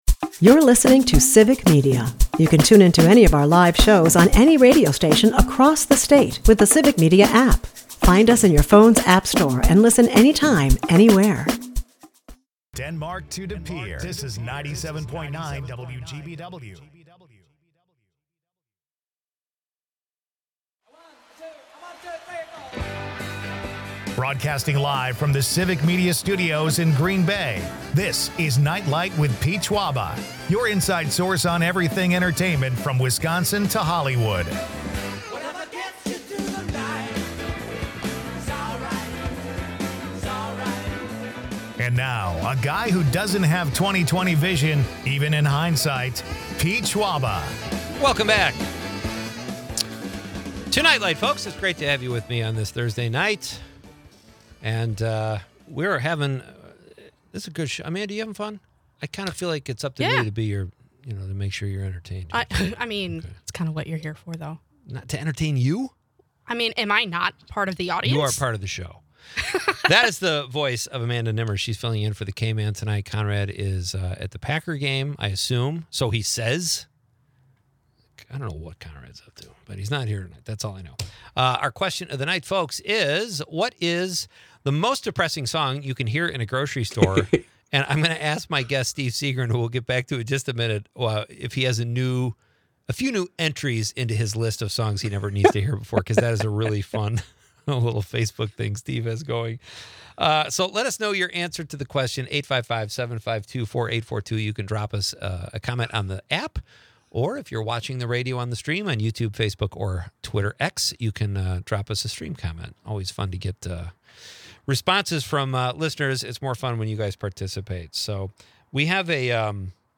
The episode also highlights a chat with Al Pitrelli, guitarist for the Trans-Siberian Orchestra, promoting their winter tour and the return of their post-show signing line. Pitrelli shares insights on balancing musical direction with performing and their charitable ticket sales. The show wraps up with light-hearted banter and upcoming event previews, including Sydney Sweeney's boxing movie and a film festival ticket giveaway.